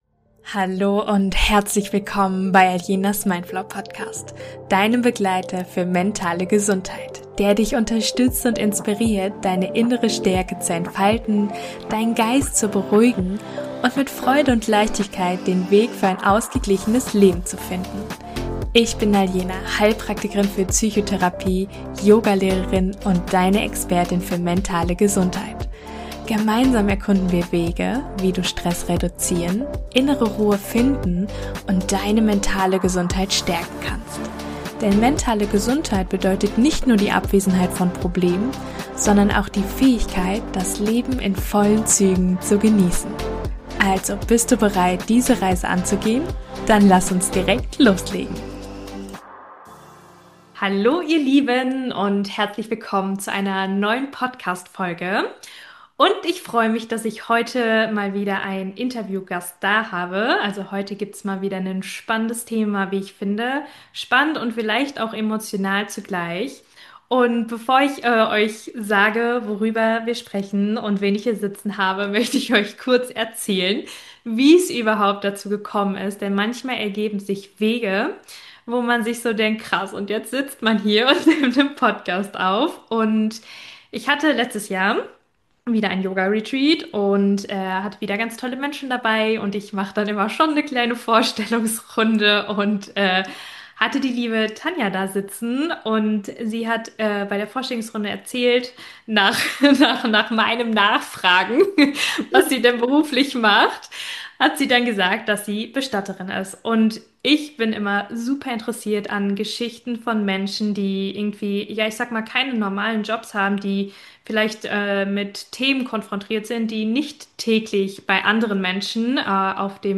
TODgeschwiegen - Über Trauer, den Tod und einen besonderen Beruf - Interview